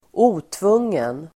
Uttal: [²'o:tvung:en]